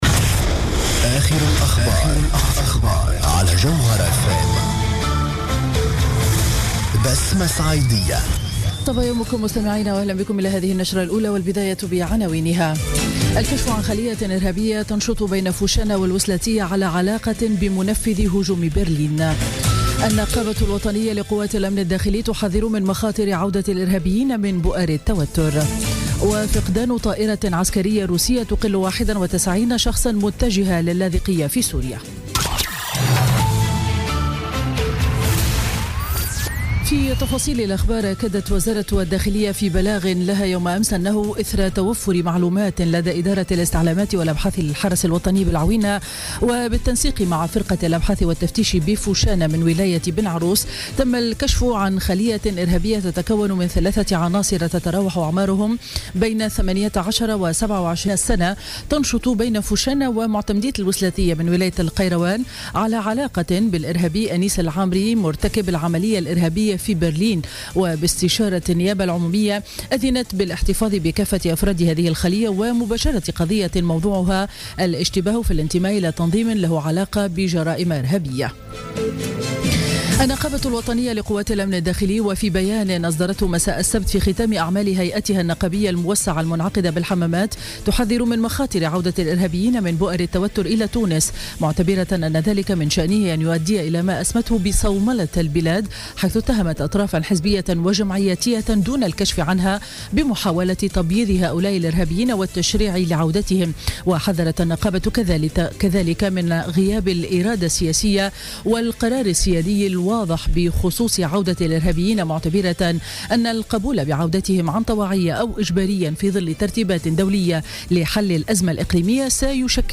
نشرة أخبار السابعة صباحا ليوم الأحد 25 ديسمبر 2016